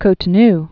(kōtn-)